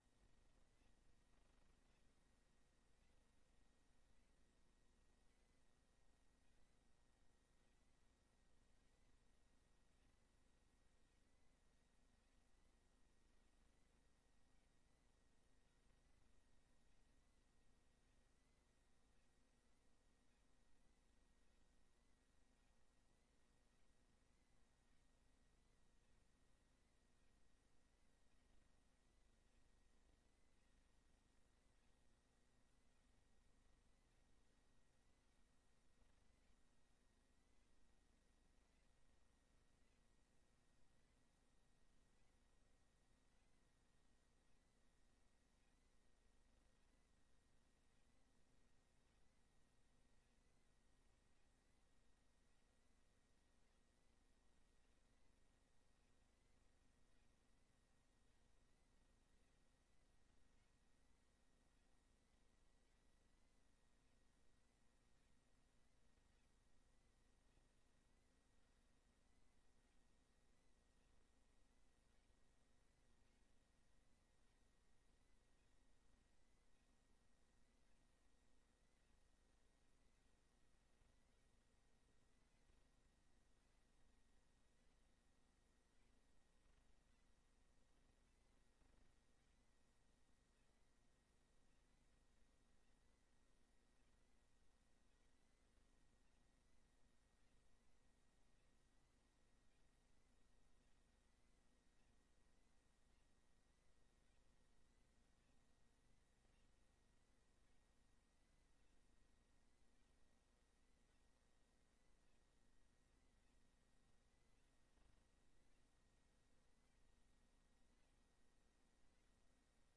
Gemeenteraad 26 maart 2025 20:00:00, Gemeente Renkum
Download de volledige audio van deze vergadering